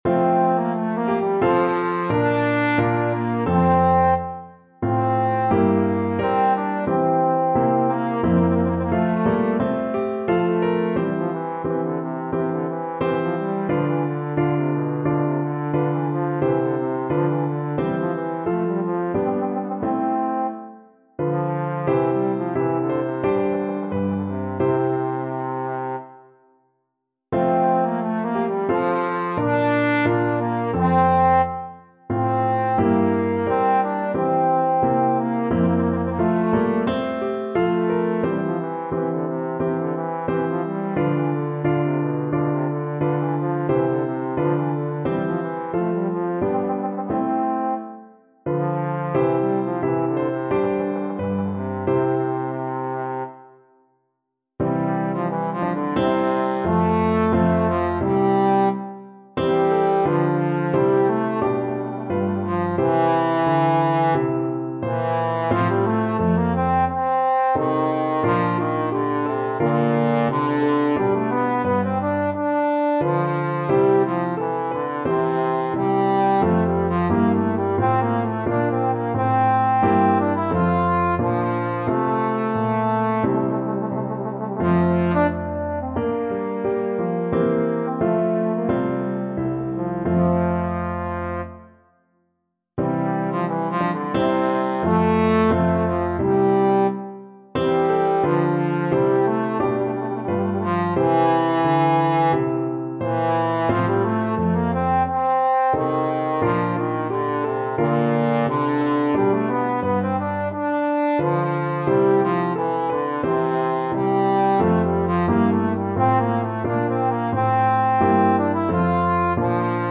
Play (or use space bar on your keyboard) Pause Music Playalong - Piano Accompaniment Playalong Band Accompaniment not yet available transpose reset tempo print settings full screen
Trombone
4/4 (View more 4/4 Music)
F major (Sounding Pitch) (View more F major Music for Trombone )
I: Adagio =44